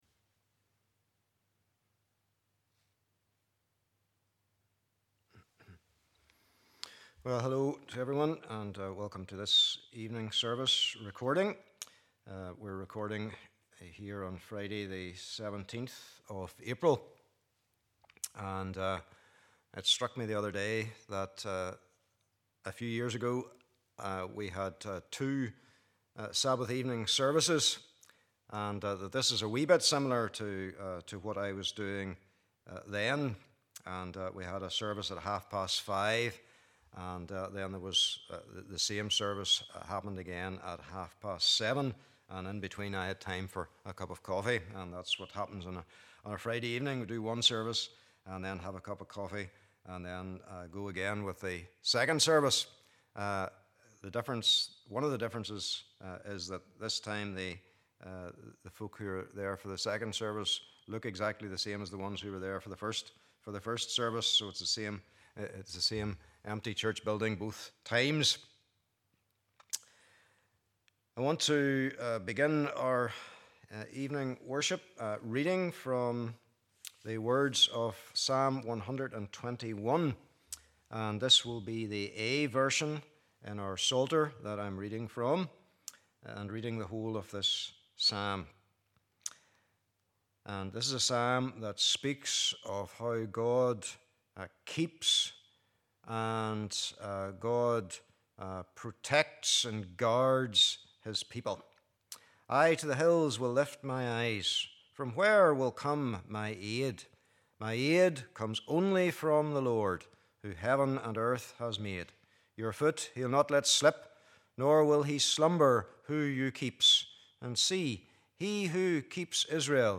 Genesis 42:25-38 Service Type: Evening Service Bible Text